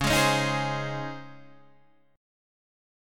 C#M7sus2 chord